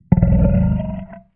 Sfx_creature_spikeytrap_idle_os_01.ogg